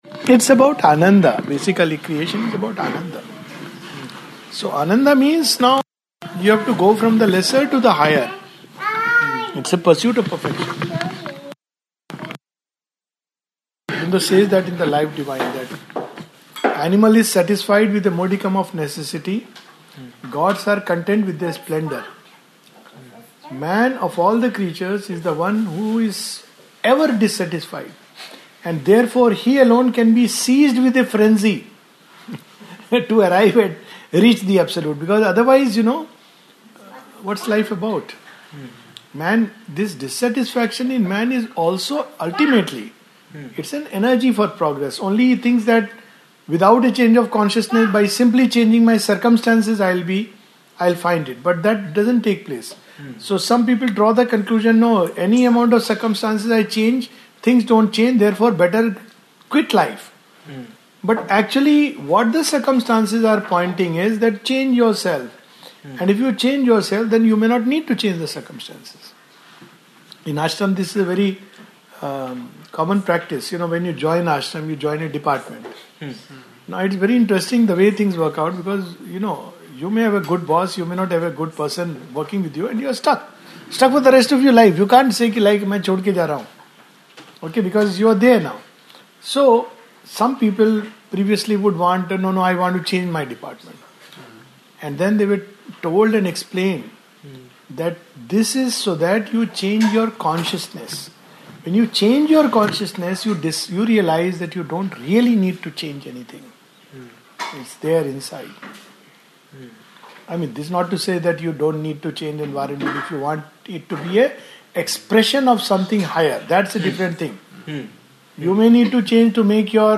a satsang
recorded in Australia in 2018&nbsp